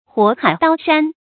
火海刀山 注音： ㄏㄨㄛˇ ㄏㄞˇ ㄉㄠ ㄕㄢ 讀音讀法： 意思解釋： 比喻極其危險和困難的地方。